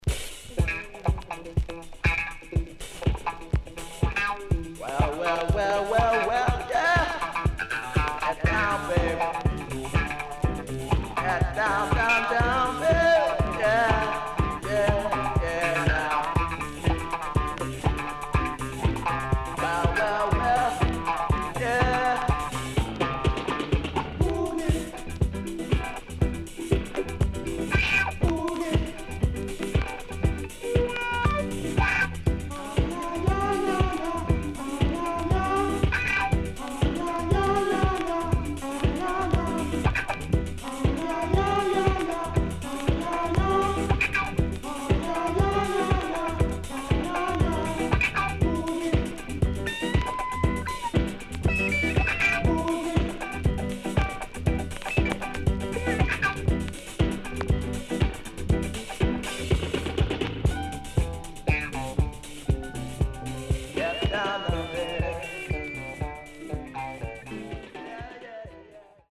Very Rare Jamaican Funky Disco